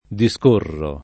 discorrere [ di S k 1 rrere ]